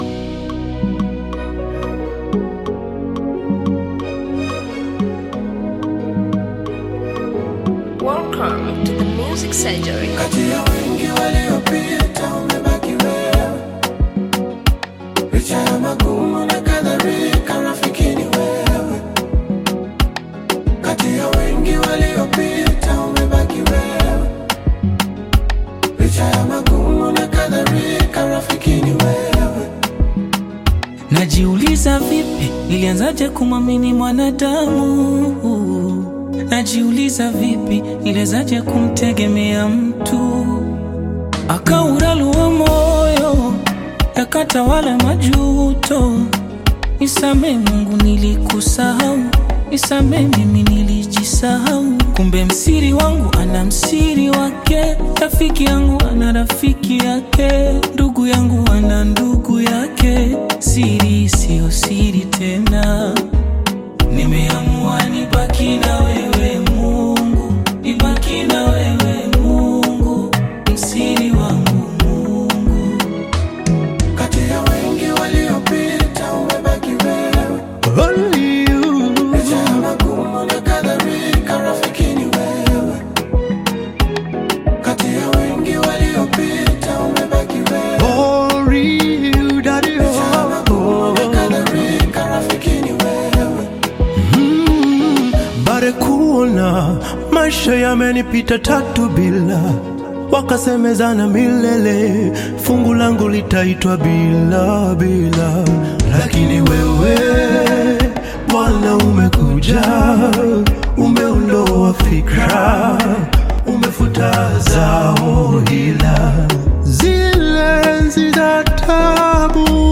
Gospel music track
Bongo Flava
Gospel song